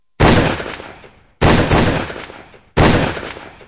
SFX连开四枪的声音音效下载
SFX音效